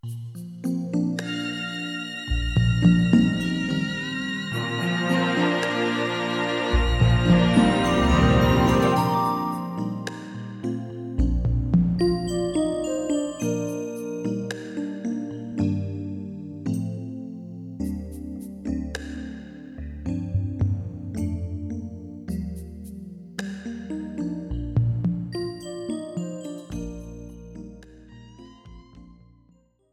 This is an instrumental backing track cover.
• Key – D
• Without Backing Vocals
• No Fade